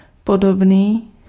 Ääntäminen
Synonyymit takový obdobný stejný Ääntäminen : IPA: [pɔdɔbniː] Haettu sana löytyi näillä lähdekielillä: tšekki Käännös Ääninäyte 1. lik {ett} 2. liknande Suku: m .